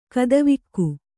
♪ kadavikku